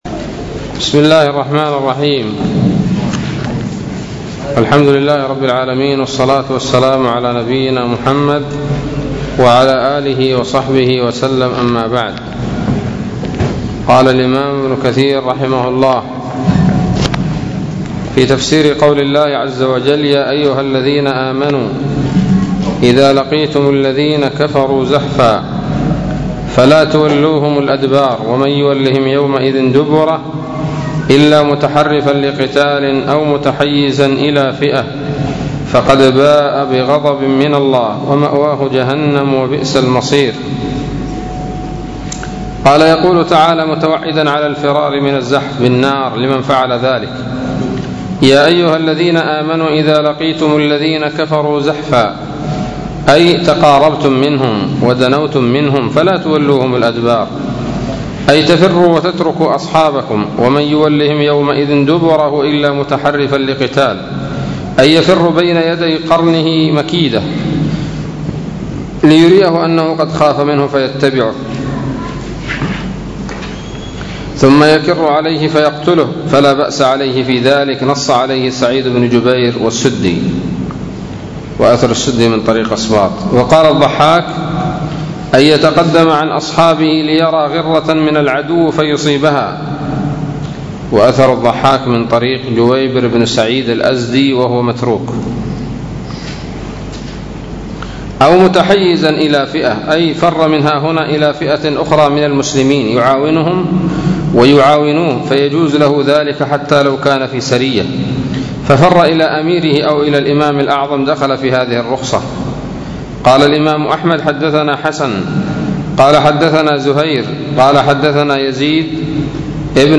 الدرس التاسع من سورة الأنفال من تفسير ابن كثير رحمه الله تعالى